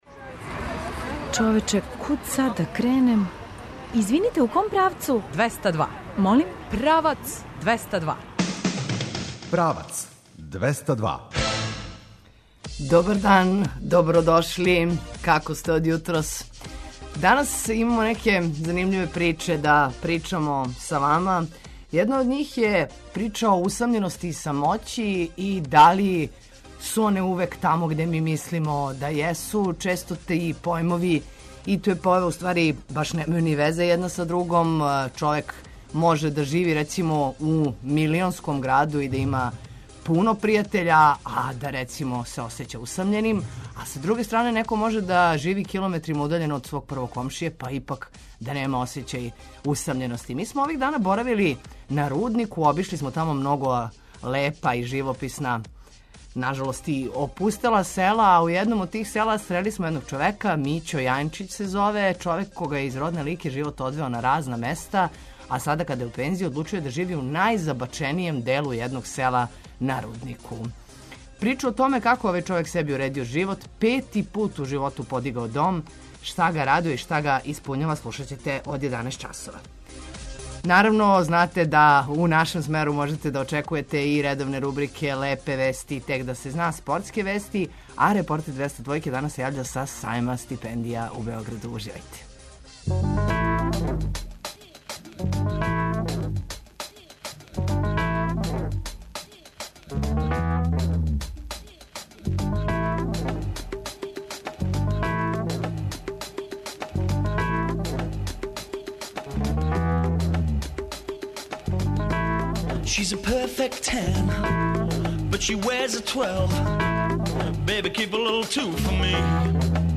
У емисији очекујте и наше редовне рубрике, „Лепе вести”, „Тек да се зна”, спортске вести а репортер 202-ке јавља се са Сајма стипендија у Београду.